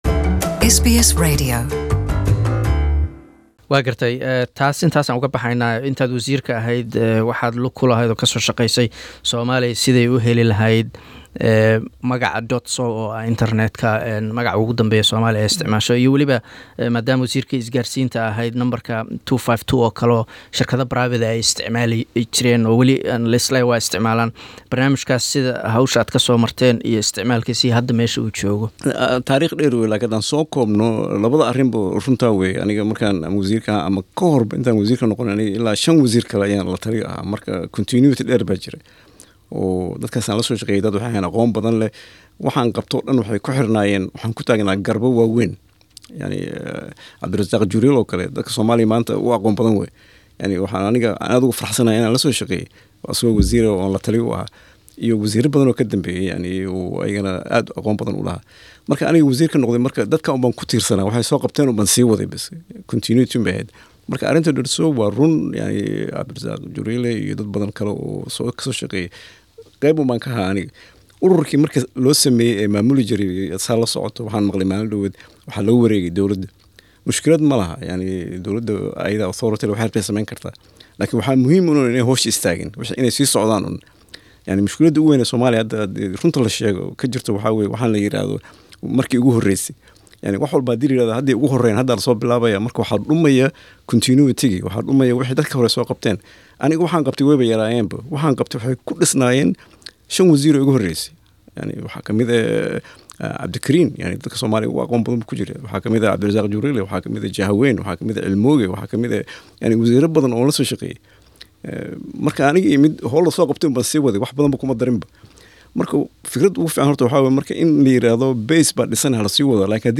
Interview: Former Somali telecommunication minister, Mohamed Ibrahim part 2